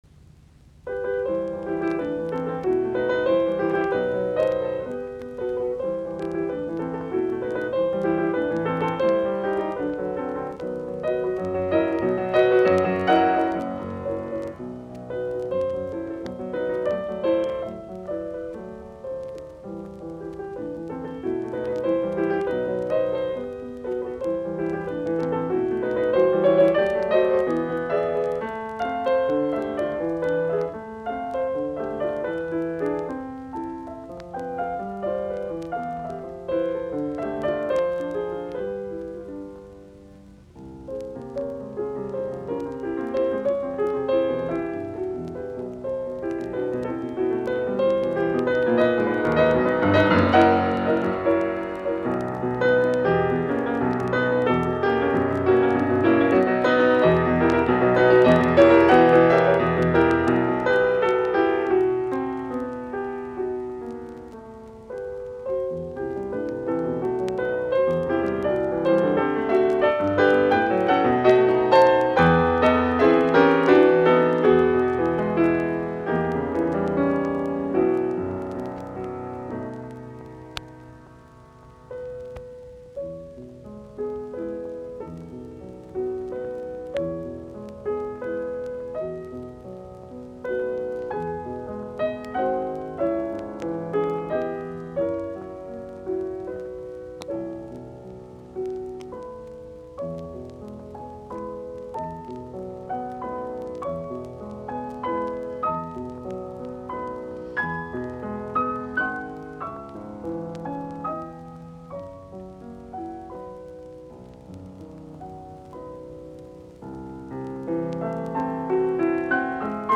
Kappaleet, piano, op119.
musiikkiäänite
Soitinnus: Piano.